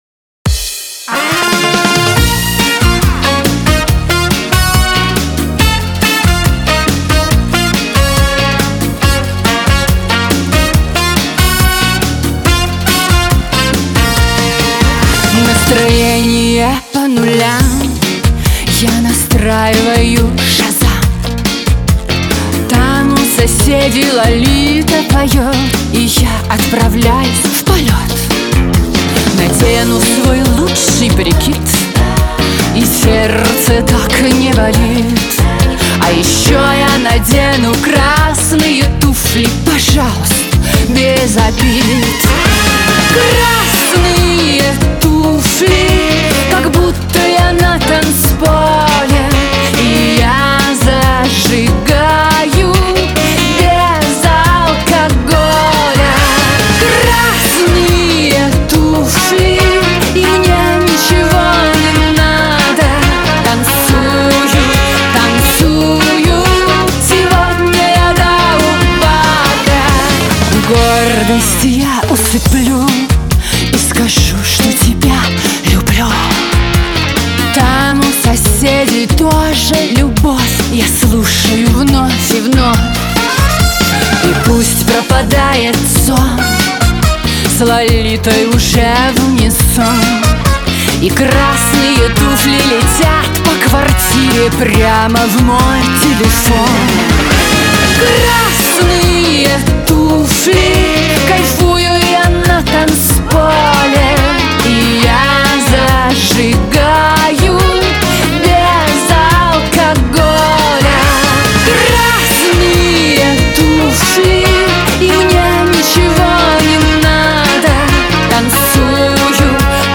dance
диско
Веселая музыка